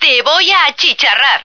flak_m/sounds/female2/est/F2burnbaby.ogg at 098bc1613e970468fc792e3520a46848f7adde96